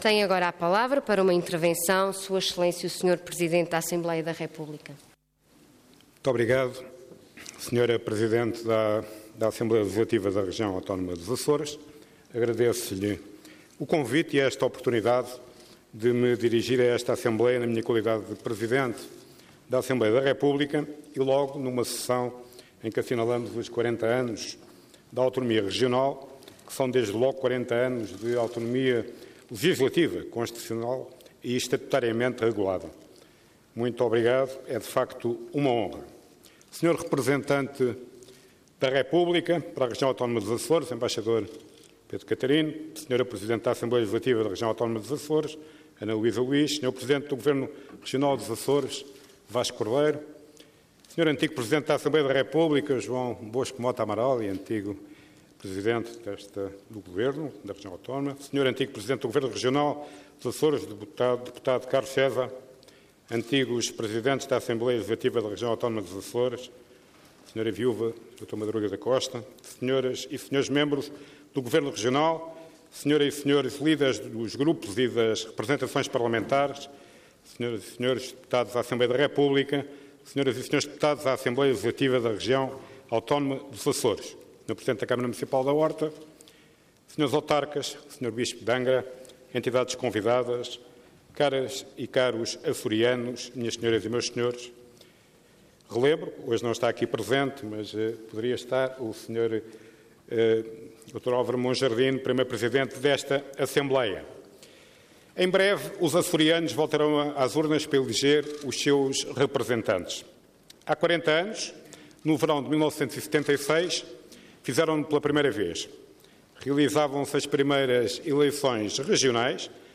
Parlamento online - Sessão Solene Evocativa dos 40 anos da Autonomia dos Açores
Intervenção Orador Eduardo Ferro Rodrigues